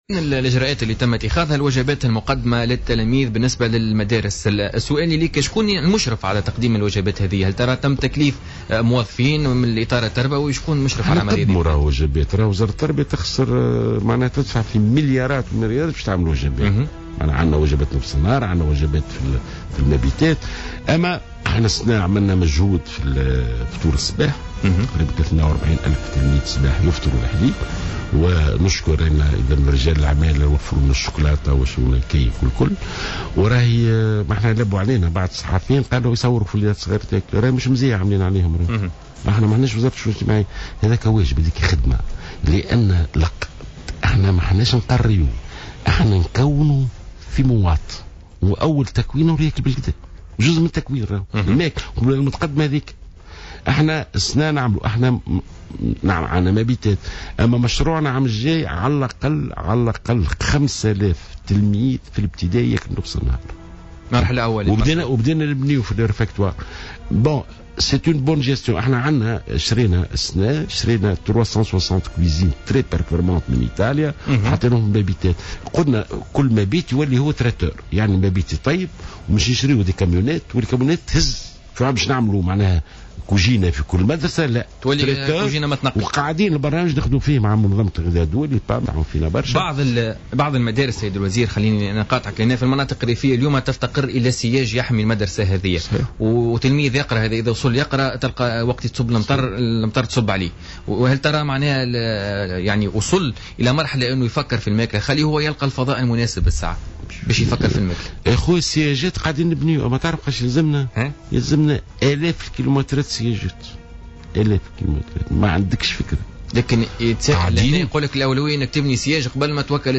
قال وزير التربية ناجي جلول في تصريح للجوهرة أف أم في برنامج بوليتكا ليوم الجمعة 22 أفريل 2016 إن 42 ألف تلميذ بالمدارس الابتدائية يتمتعون بلمجة صباحية منذ أن شرعت الوزارة في تطبيق هذه التجربة.